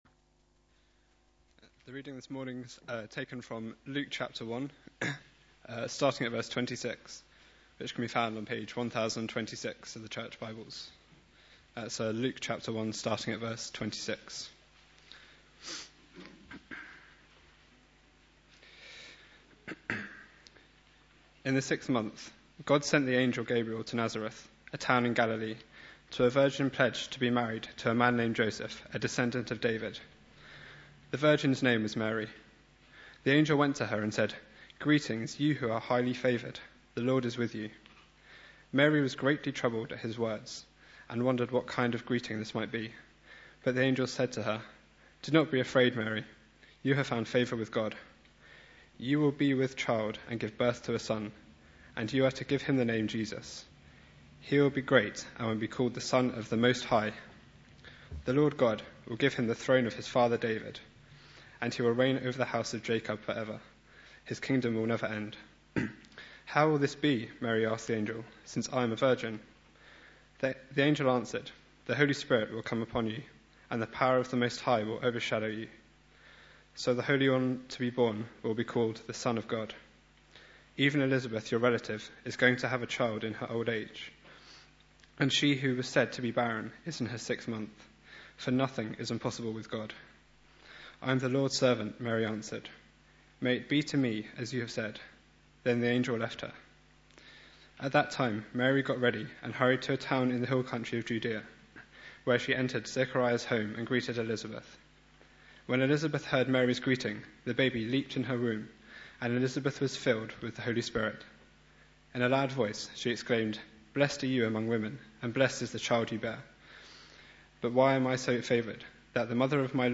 Media for Sunday Service on Sun 16th Dec 2012 10:00
Passage: Luke 1.27-56 Series: The Christmas Story in Luke's Gospel Theme: Sermon